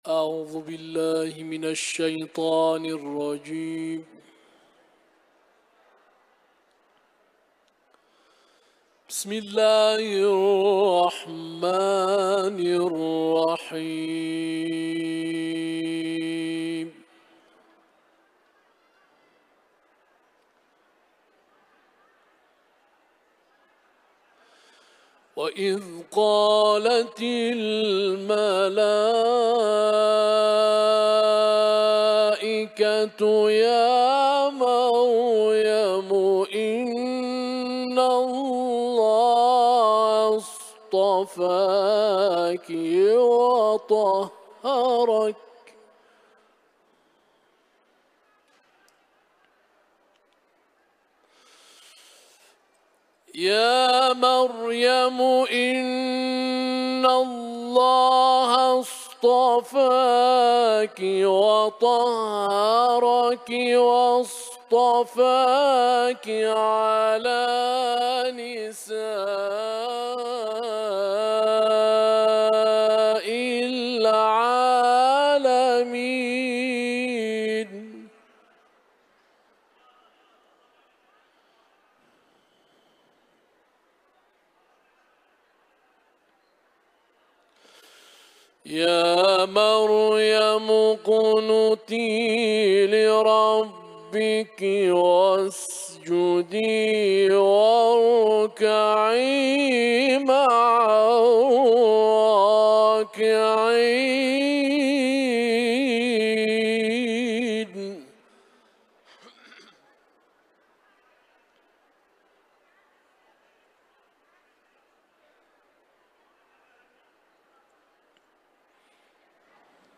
Etiketler: İranlı kâri ، Kuran tilaveti ، Ali imran suresi